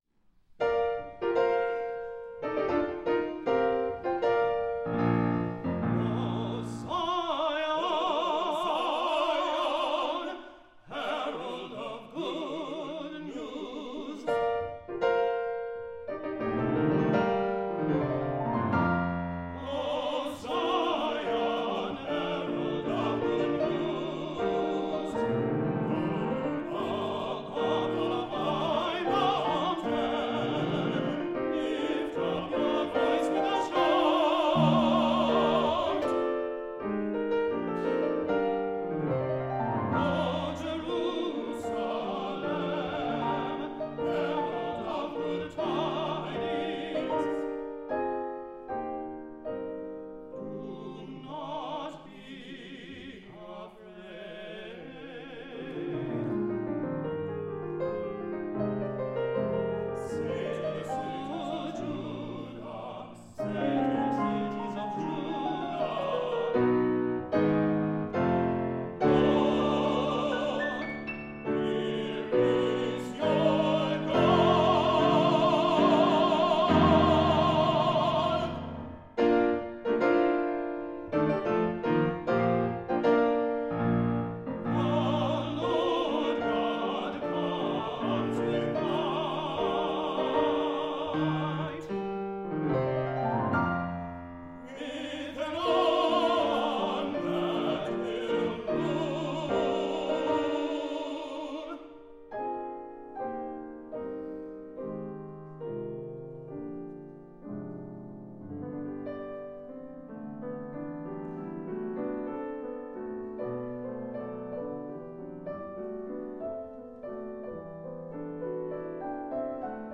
tenor
baritone
piano
Duet for tenor and baritone, piano